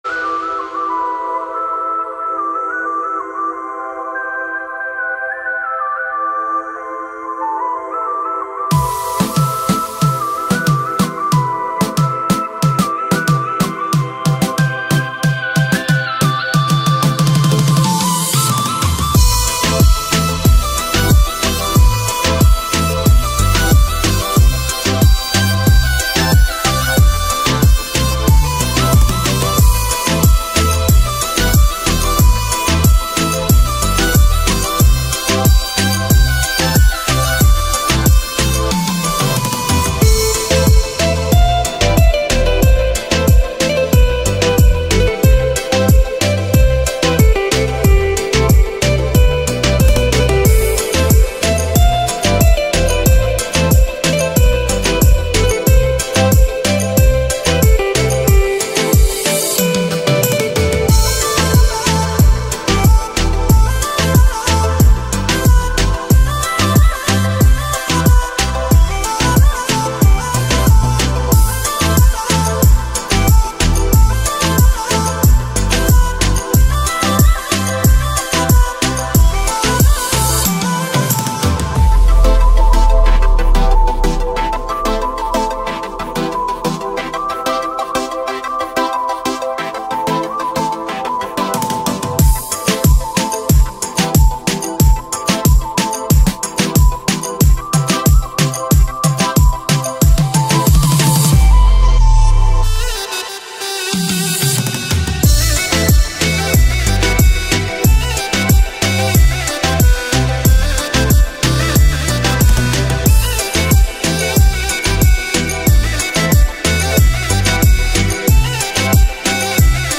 Balkanik Instrumental music